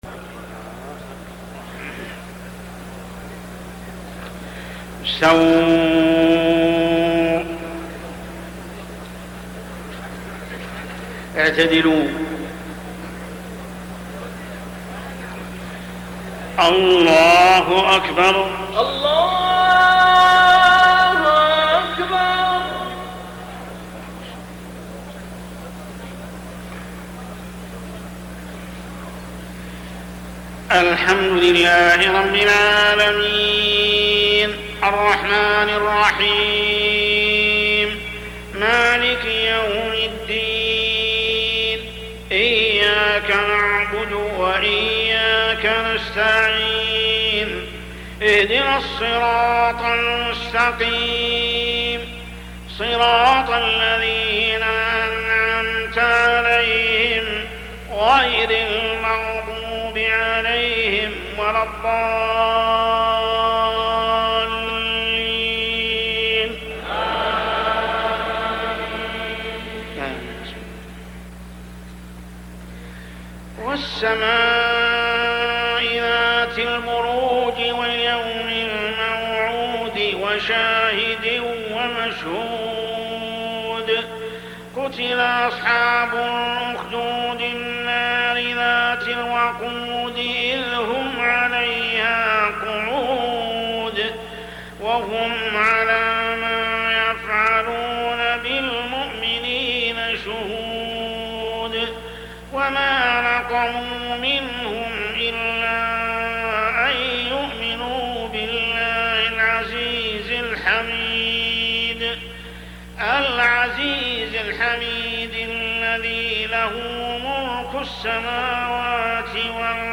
صلاة العشاء من شهر رمضان عام 1421هـ سورة البروج كاملة | Isha prayer Surah AL-BURUJ > 1421 🕋 > الفروض - تلاوات الحرمين